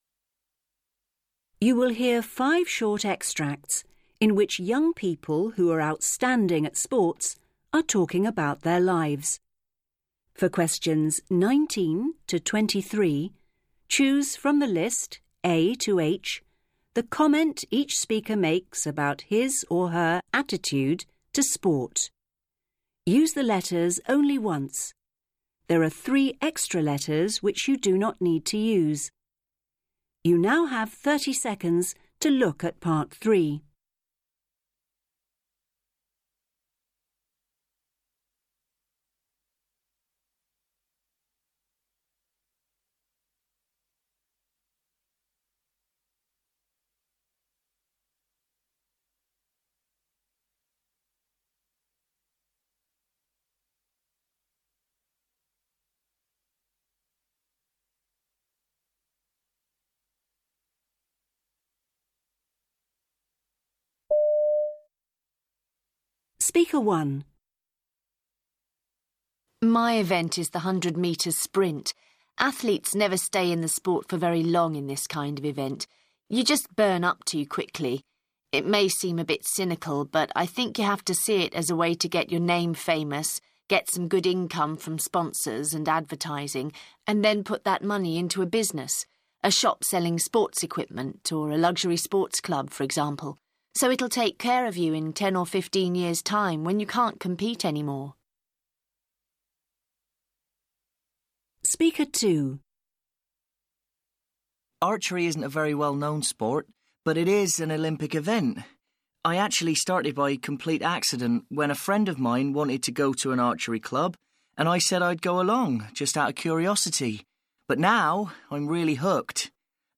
You will hear five short extracts in which young people who are outstanding at sports are talking about their lives.
You will hear an interview with someone who has started a magazine for children.